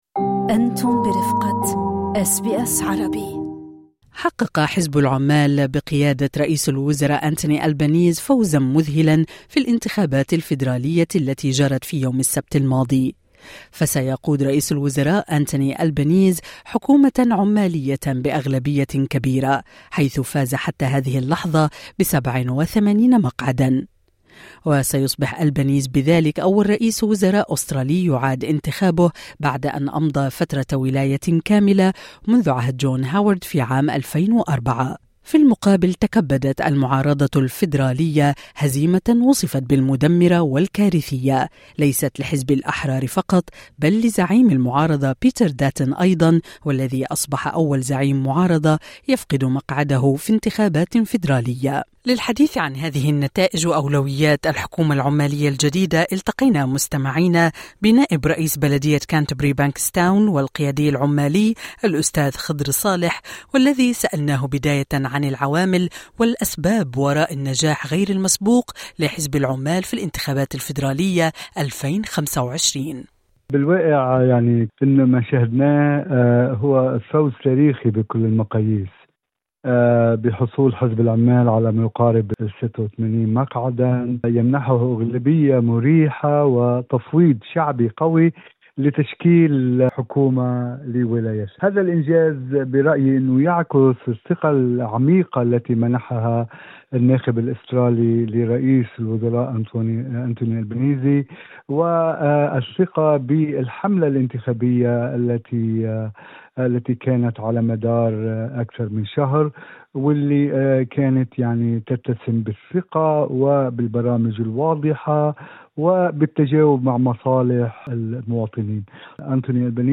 وفي حديث مع اس بي اس عربي قال نائب رئيس بلدية كانتربيري بانكستاون والقيادي العمالي الأستاذ خضر صالح هذا تفويض شعبي قوي يعكس الثقة العميقة برئيس الوزراء وببرامج العمال التي تجاوبت مع مصالح المواطنين